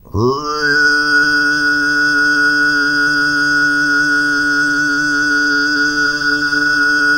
TUV1 DRONE04.wav